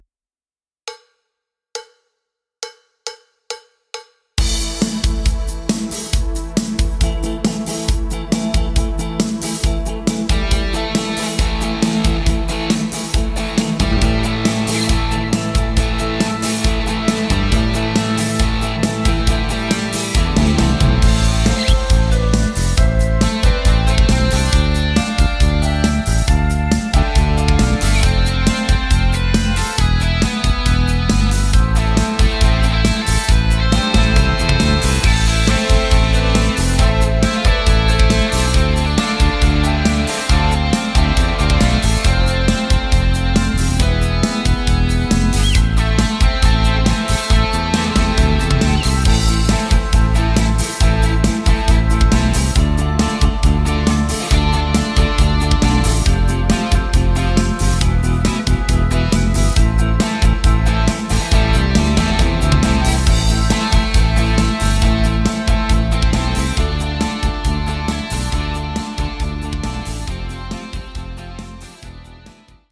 Playback, Karaoke, Instrumental